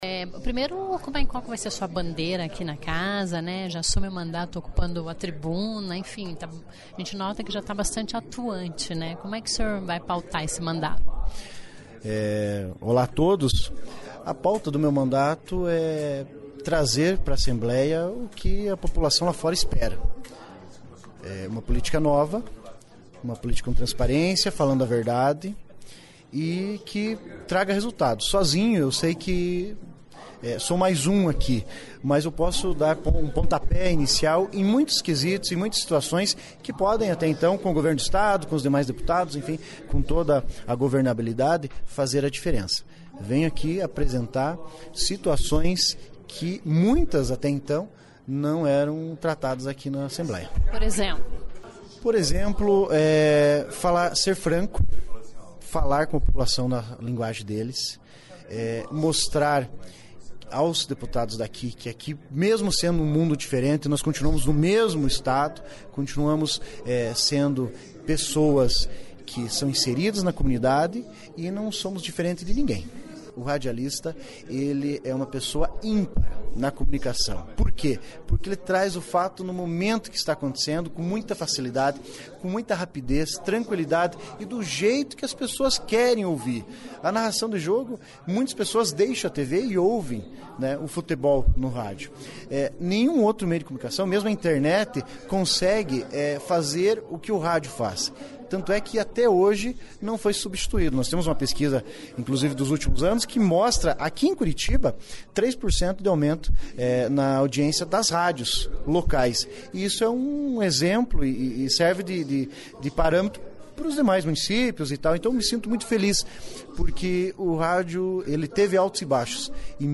Ao falar das principais bandeiras como deputado estadual, Emerson Bacil (PSL) lembra a importância do rádio, no Dia Mundial do Rádio, em 13 de fevereiro. Ouça a entrevista do parlamentar de primeiro mandato.